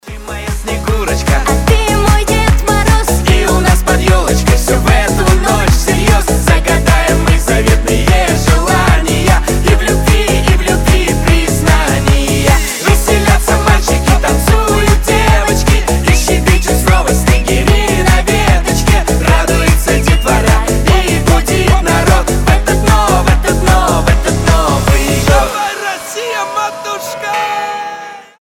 • Качество: 320, Stereo
позитивные
зажигательные
веселые
заводные
дуэт
праздничные
динамичные
куранты